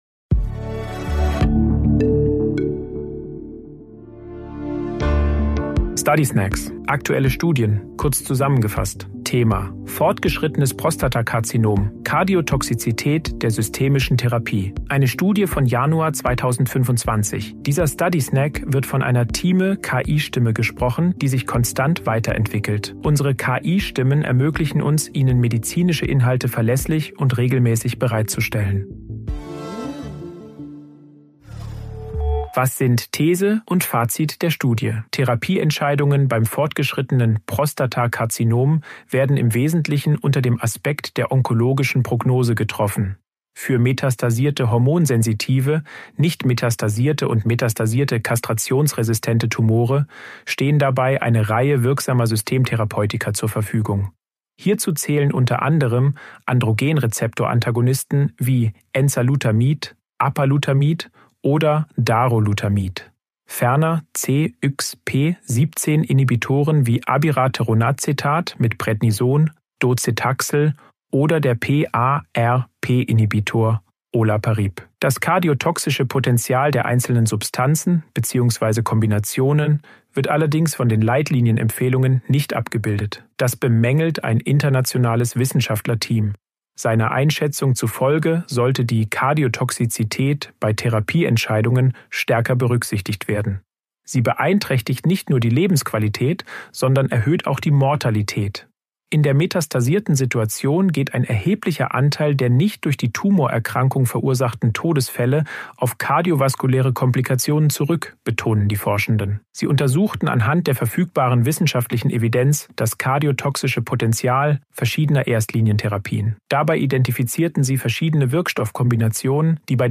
Übersetzungstechnologie gesprochene Texte enthalten